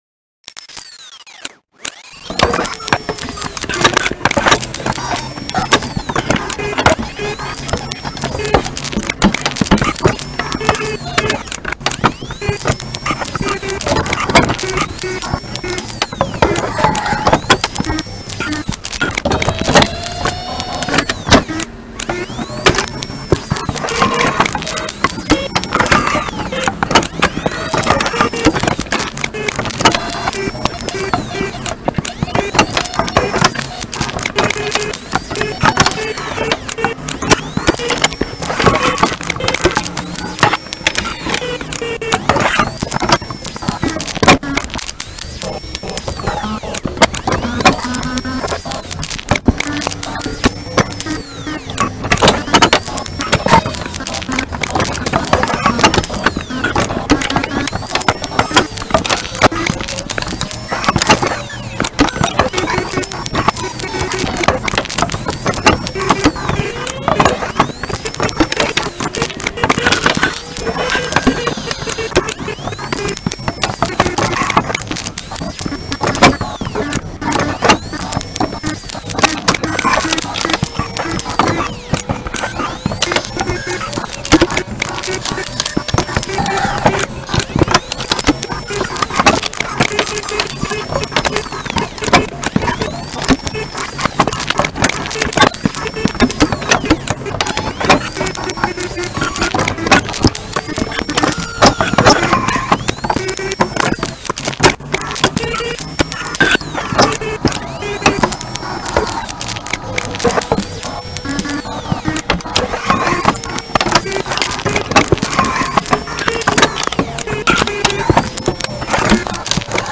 Noisy action with cassette recorder and codes